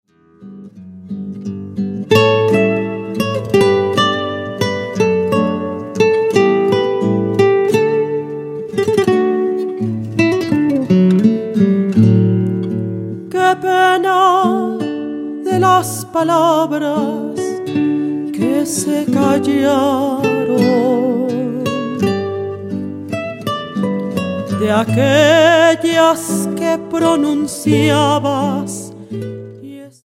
mezzosoprano
guitarras